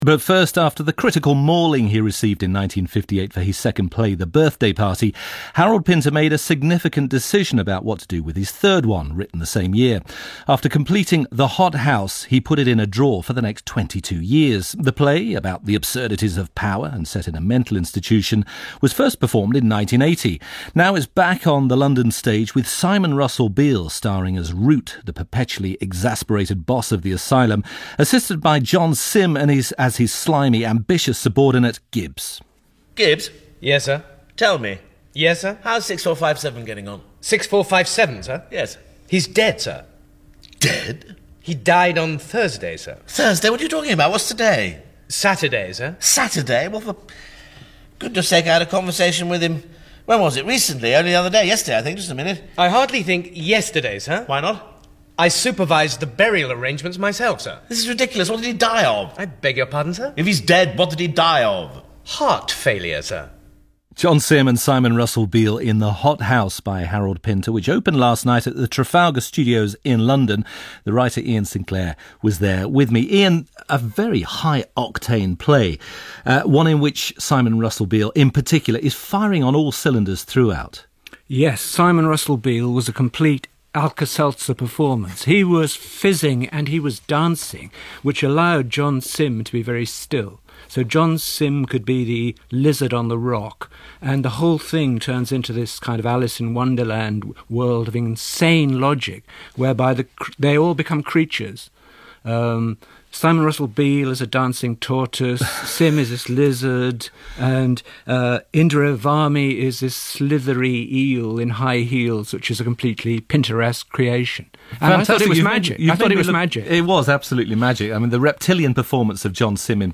Writer Iain Sinclair delivers his verdict on the play about a bureaucratic mental institution run by a sadist.
Listen to scene clip with John Simm as Gibbs and Iain’s review (click play button) below: